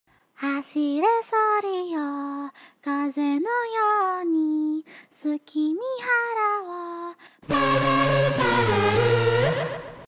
PADORU PADORU~~~ [happy] sound effect sound effects free download